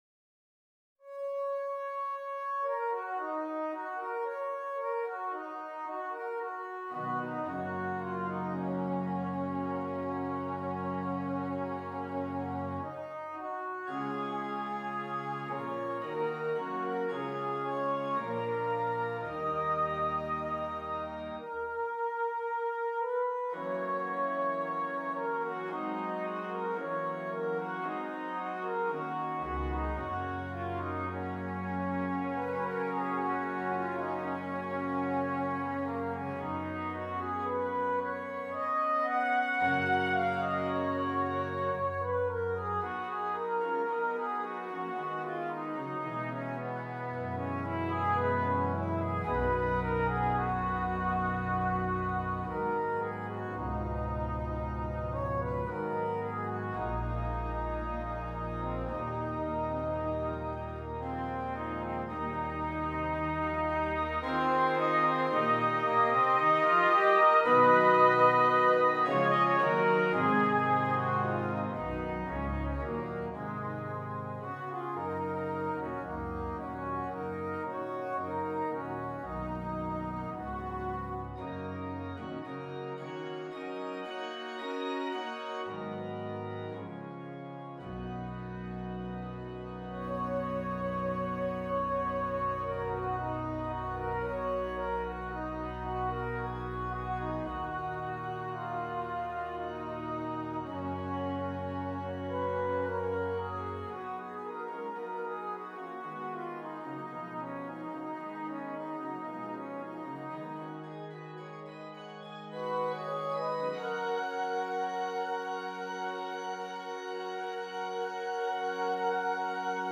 2 Trumpets and Keyboard
for 2 trumpets and keyboard